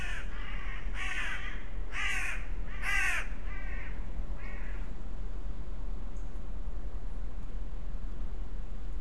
Tawny owl  19/8/22 02:02